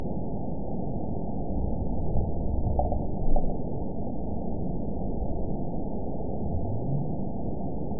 event 918032 date 04/27/23 time 08:22:34 GMT (2 years ago) score 9.41 location TSS-AB03 detected by nrw target species NRW annotations +NRW Spectrogram: Frequency (kHz) vs. Time (s) audio not available .wav